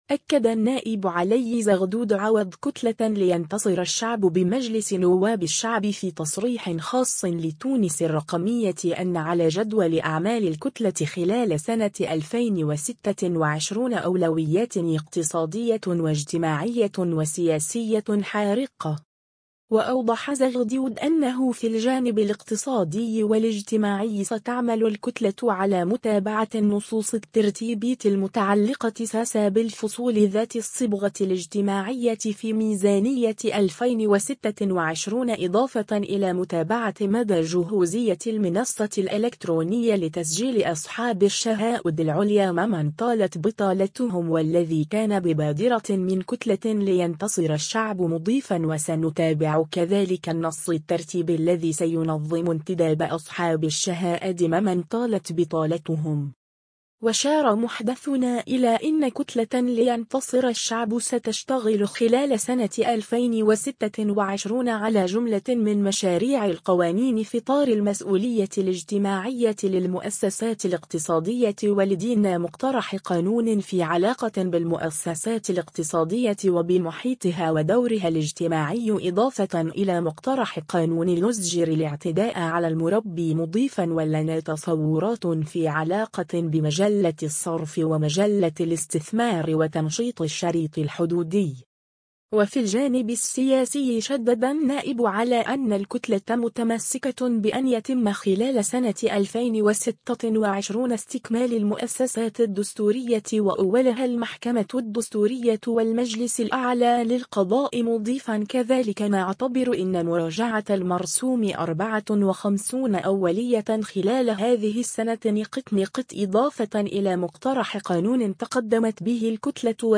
أكد النائب علي زغدود عوض كتلة لينتصر الشعب بمجلس نواب الشعب في تصريح خاص لـ”تونس الرقمية” أن على جدول أعمال الكتلة خلال سنة 2026 أولويات اقتصادية واجتماعية وسياسية حارقة.